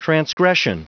Prononciation du mot transgression en anglais (fichier audio)